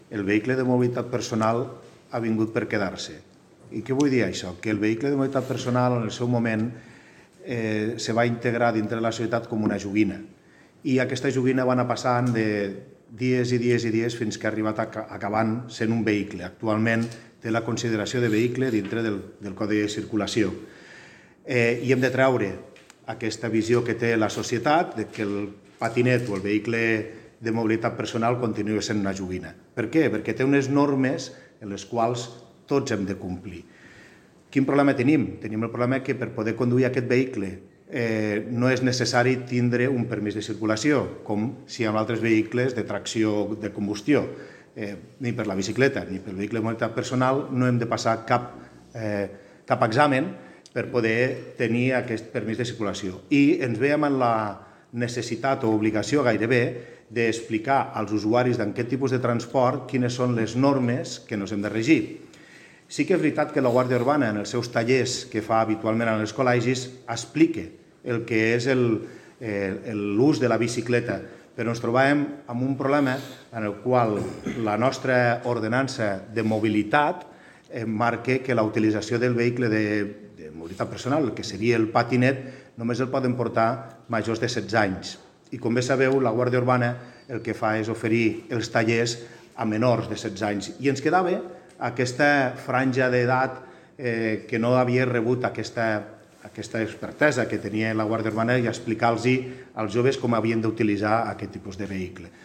tall-de-veu-del-regidor-de-mobilitat-joan-ramon-castro-sobre-el-nou-projecte-de-mobilitat-urbana-responsable